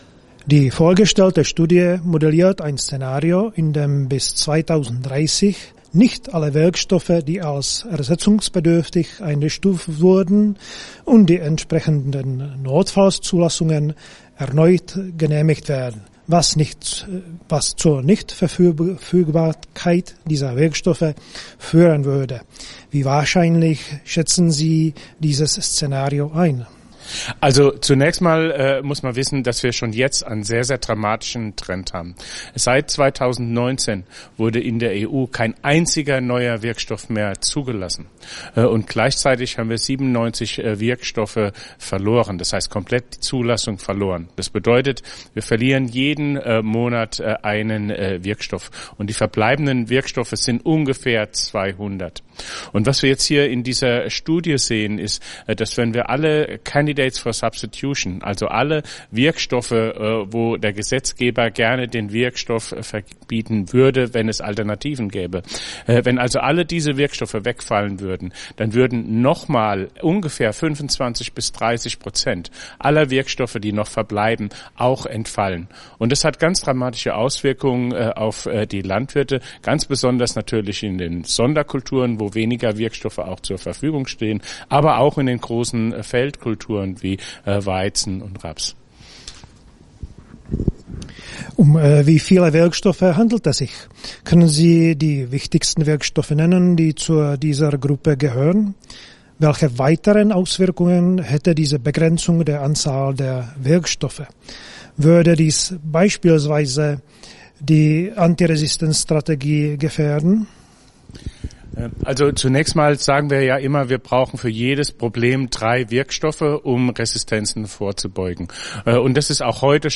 BASF-interview-final.mp3